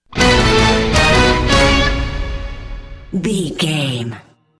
Ionian/Major
C#
horns
drums
electric guitar
synthesiser
driving drum beat